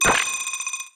UIBeep_Fast Beeps Decay.wav